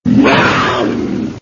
دانلود صدای ببر از ساعد نیوز با لینک مستقیم و کیفیت بالا
جلوه های صوتی